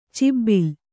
♪ cobbil